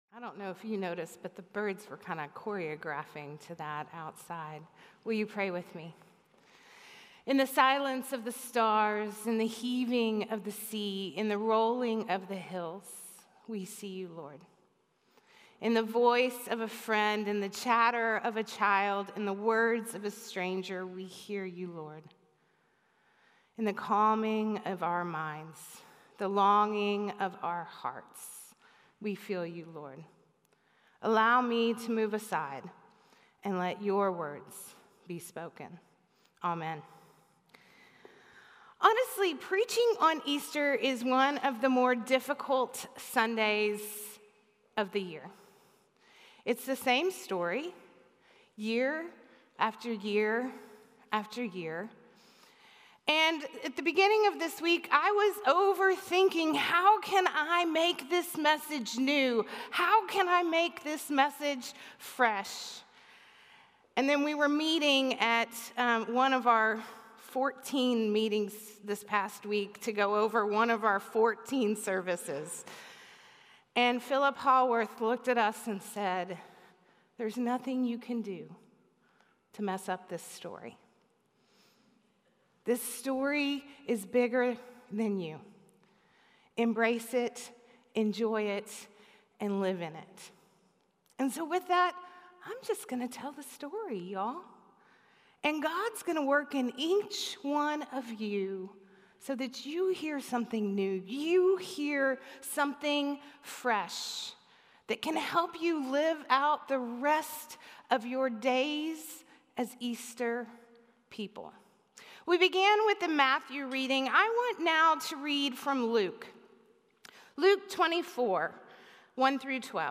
A message from the series "Easter 2025."